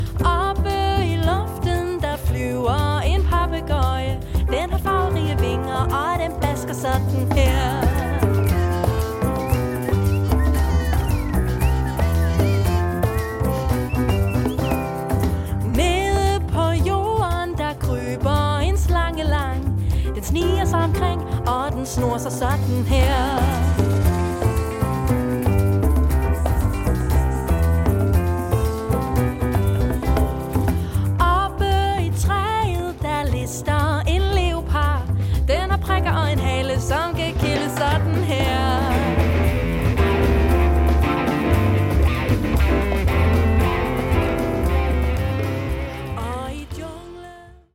• Originale børnesange
Vokal
Trompet